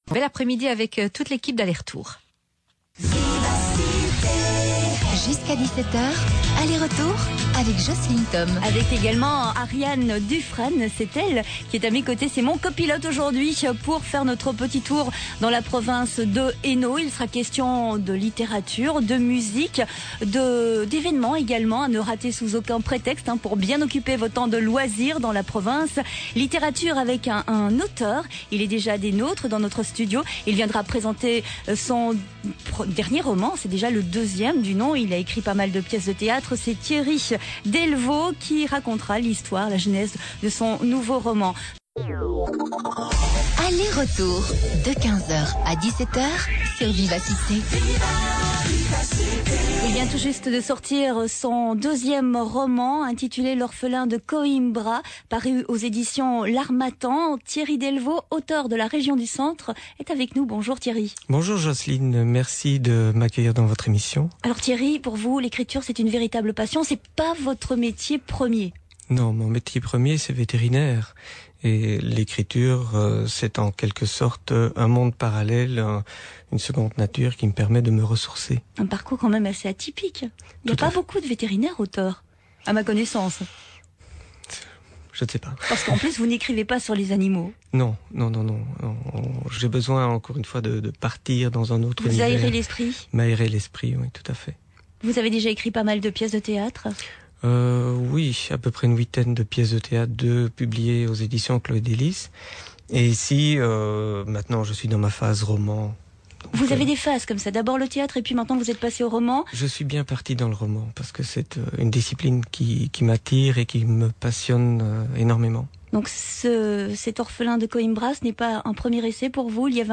Émission Radio
Interview sur l'antenne de Vivacité Mons